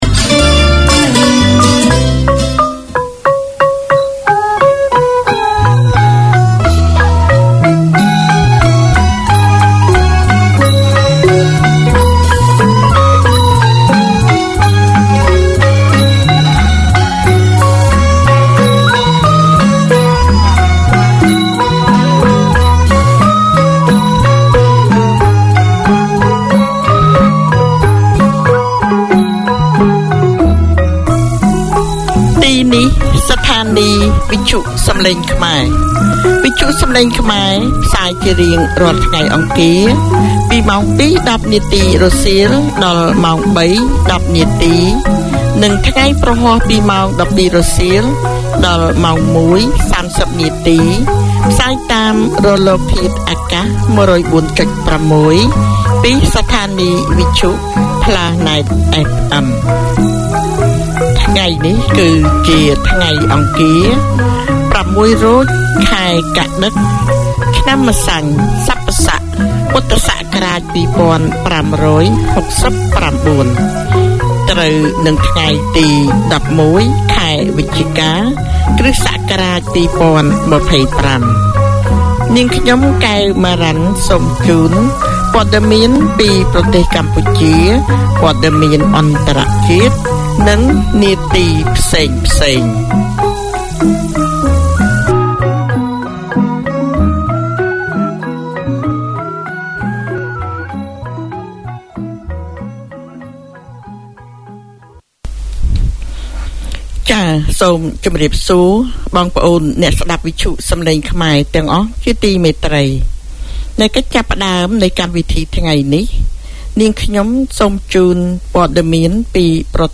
Community Access Radio in your language - available for download five minutes after broadcast.
This half hour series features interviews and discussions on matters of interest to women in general and migrant women in particular. Women working in the community talk to women with shared experiences, to people who can help, to female achievers.